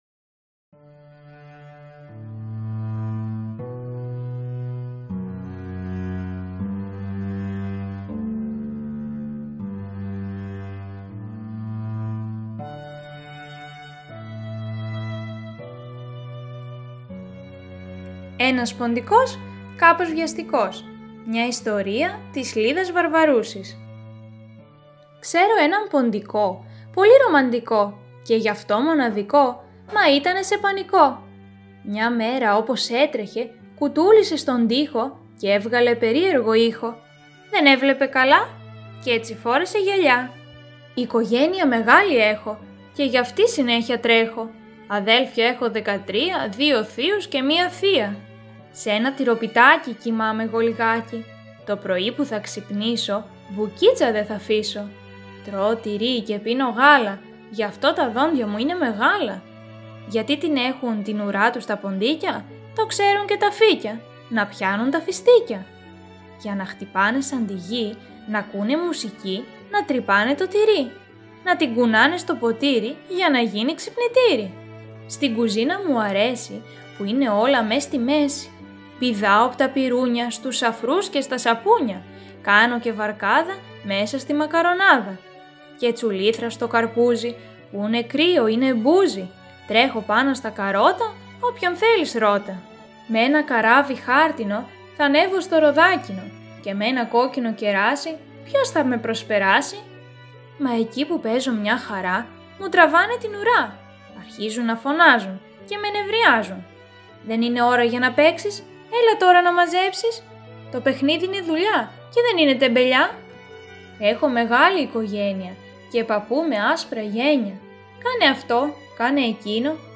Βιβλιοθήκη Ψηφιακής Αφήγησης